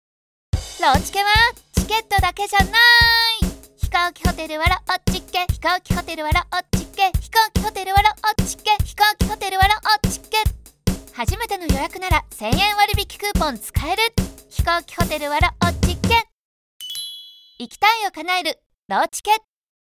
繰り返しのメッセージでも耳残りの良い音声CMになるよう、リズミカルなBGMを組み合わせています。